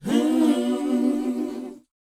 WHOA A BD.wav